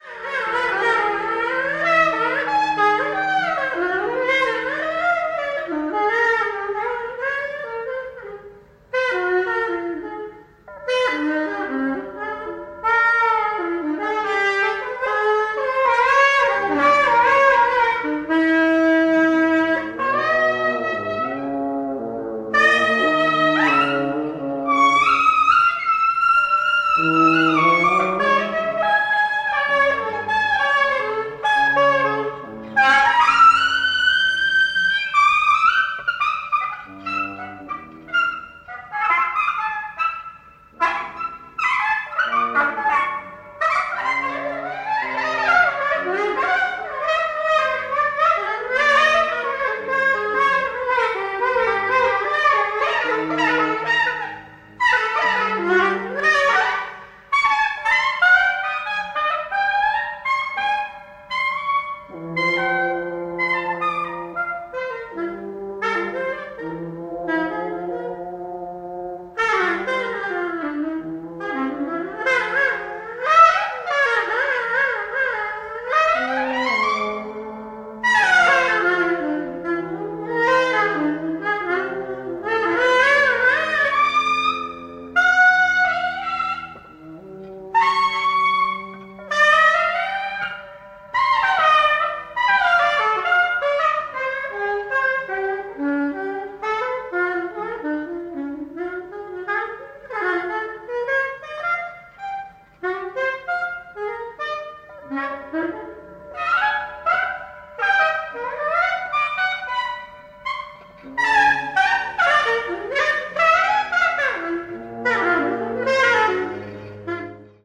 Genre:ElectronicJazz
Style:AmbientAvantgardeExperimentalFree Improvisation